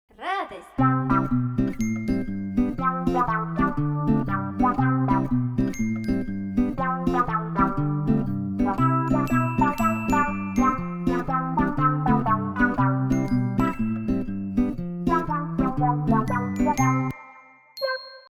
Музыкальная зарисовка должна начинаться с объявления голосом настроения.
Ниже представлены примеры, созданные во время музыкальных занятий на наших курсах по программе GarageBand.
"Радость" - Записана на занятиях первой ступени. "Базовый курс, экспресс-метод сочинения музыки" (работа с лупами, подключение MIDI-клавиатуры, программные инструменты, запись голоса).